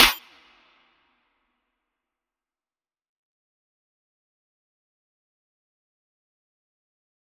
DMV3_Clap 13.wav